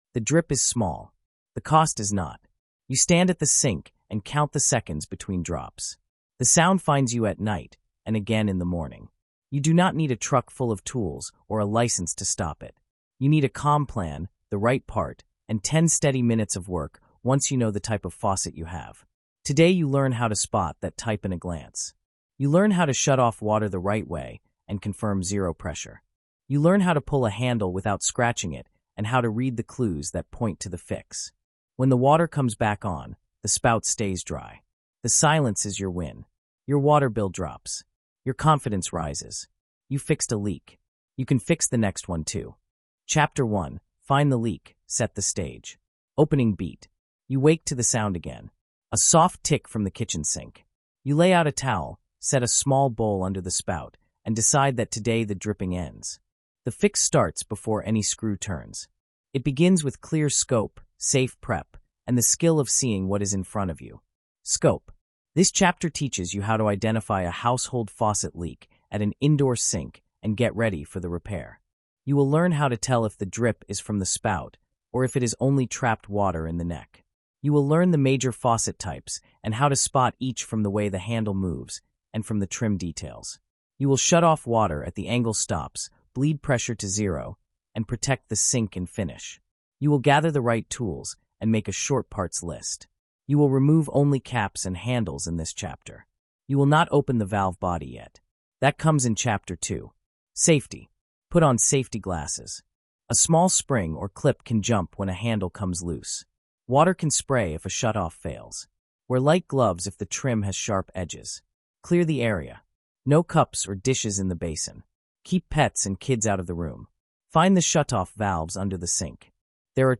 The tone is calm, practical, and step-locked for audio.